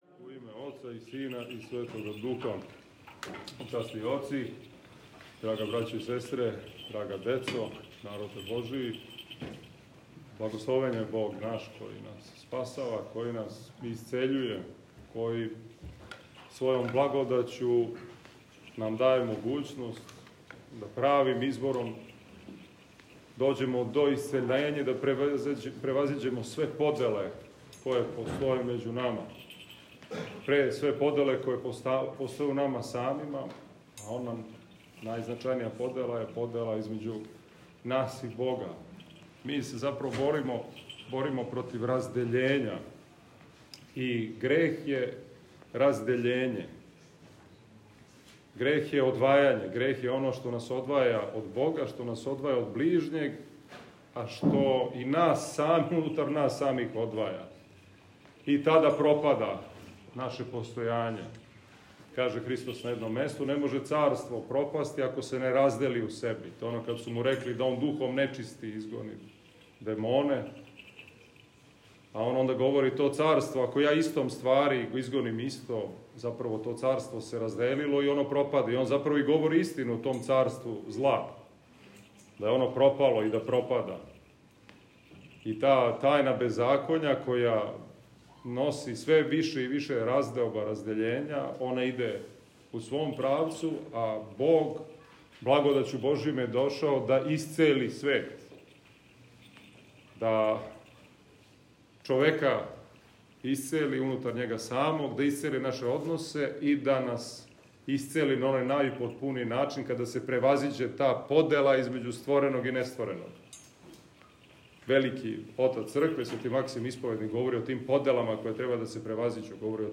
Његово Преосвештенство викарни Епископ новобрдски г. Иларион је у недељу трећу по Духовима, 25. јуна 2023. године, свету Литургију, уз саслужење свештеника и ђакона АЕМ и молитвено учешће верујућег народа Божијег, служио у храму св. великомученика Трифуна Кампсада на Топчидерском гробљу.
Звучни запис беседе